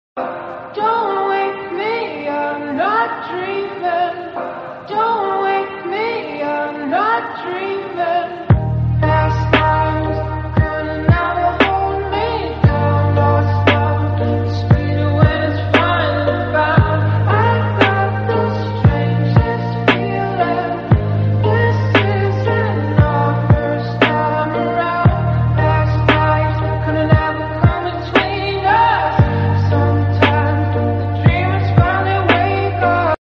The lights, the reflections, and the calm vibes – perfect moment to share with you all.